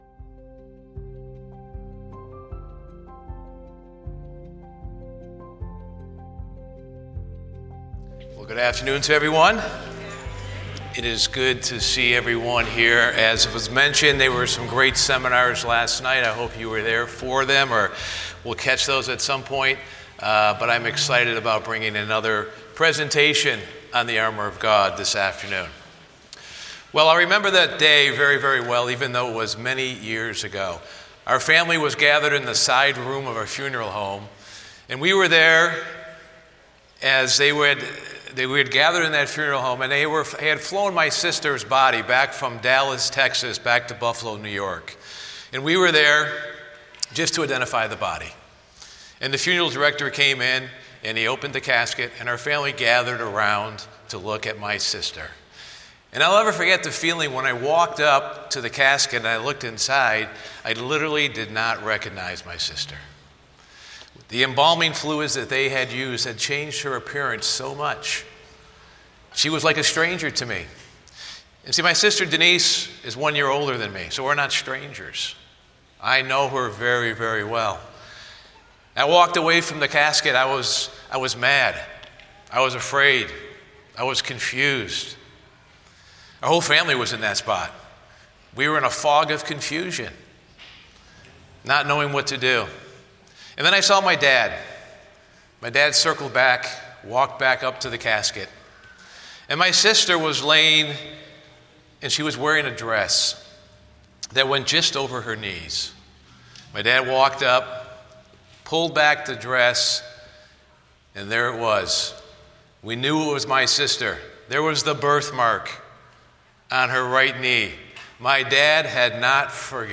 This seminar was given during the 2024 Winter Family Weekend.